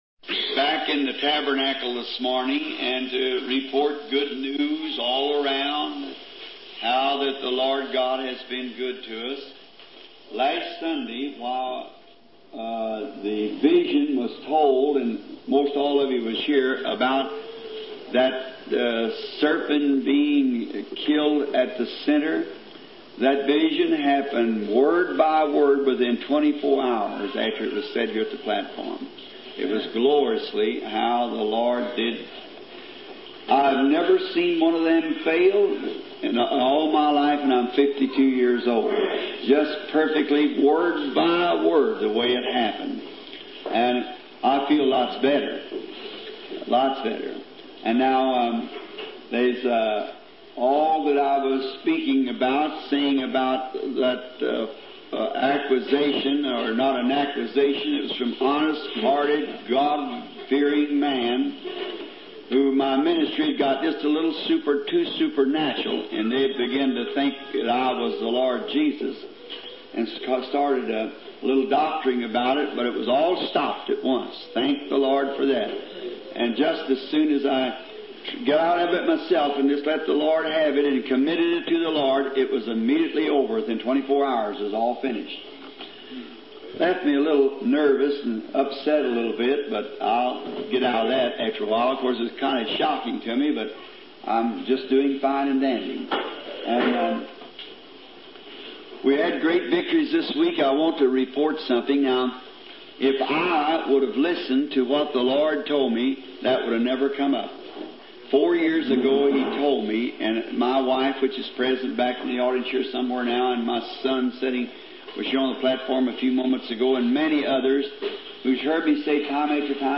aufgezeichneten Predigten